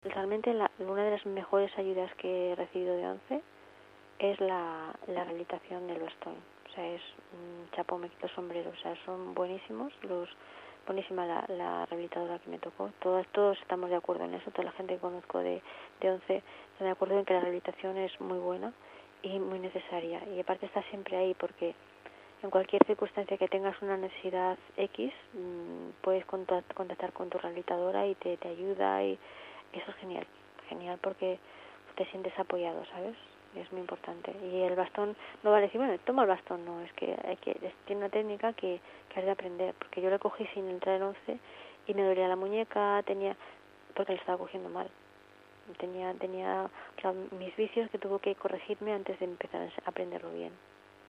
suena cercana, increíblemente dulce, aniñada, con colores de tonos suaves, casi infantiles.